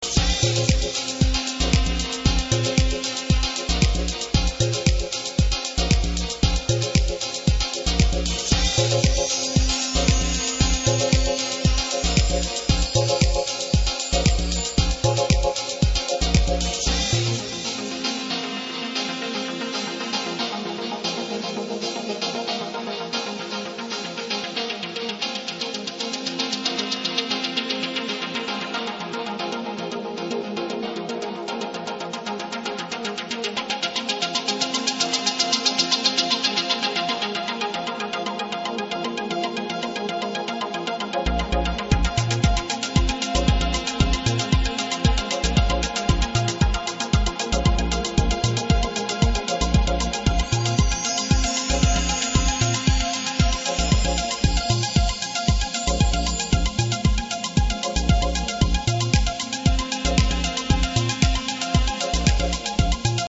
eine wunderbare kleine Househymmne
Leichtfüßige 114 BPM tragen durch kurzweilige 10 Minuten.